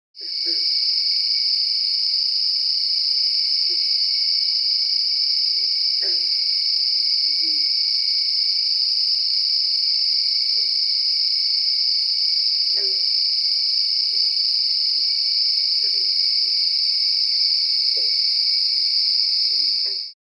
A lush, dense recording of a warm New England summer evening at the edge of a pond. Insects on the shore are at their peak, singing a mesmerizing chorus, while Green Frogs in the water add their own vocal punctuation to the soundscape. Great for relaxation, meditation, or sleep.
Evening-by-the-Pond-sample.mp3